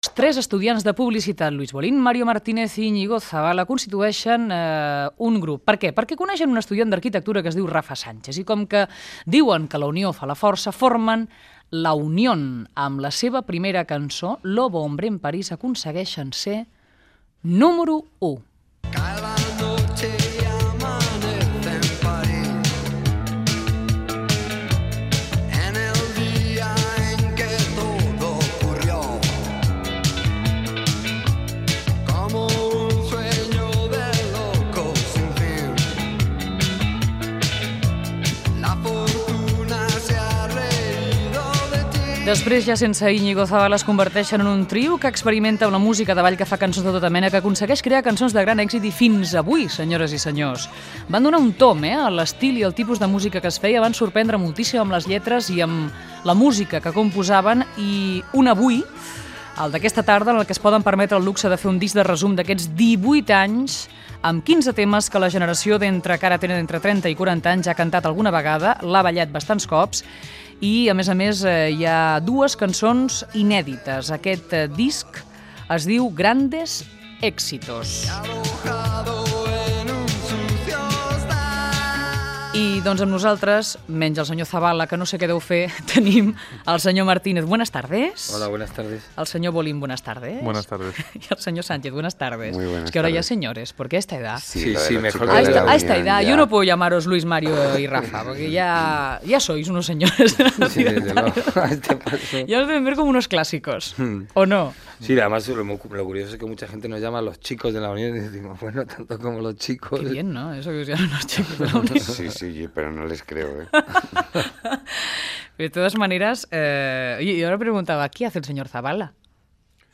Fragment d'una entrevista al grup musical "La Unión" sobre el seu disc "Grandes éxitos".